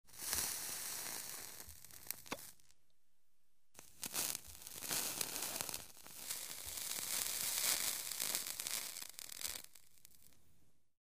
Звук затяжки сигаретой